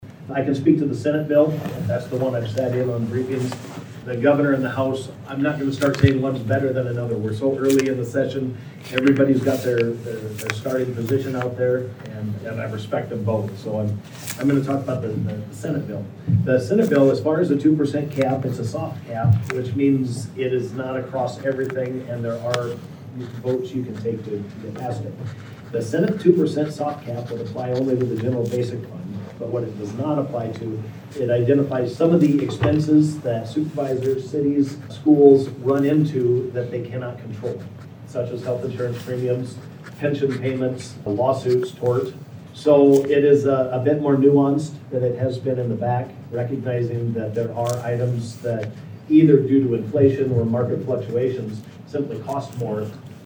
Pictured: Rep. Craig Williams (left) and Sen. Jason Schultz (right) speak during the Jan. 24 Legislative Forum at St. Anthony Regional Hospital